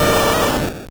Cri de Carabaffe dans Pokémon Or et Argent.